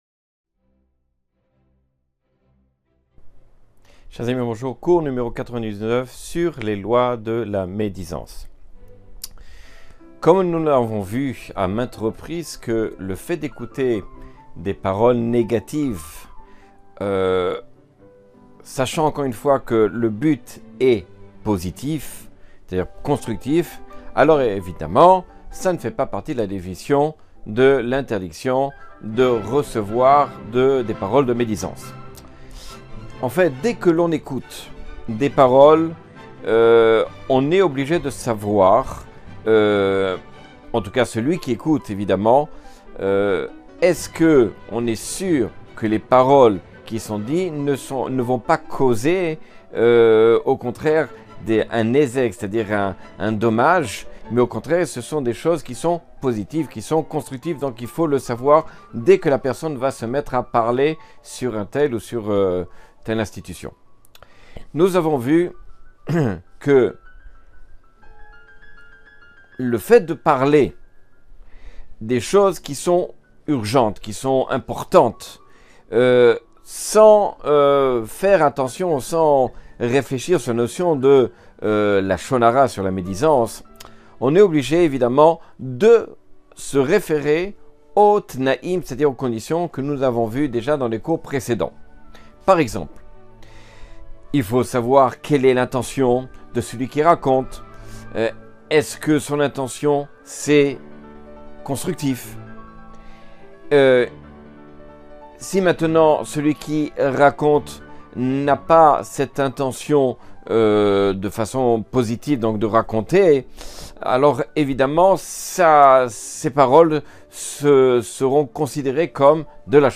Cours 99 sur les lois du lashon hara.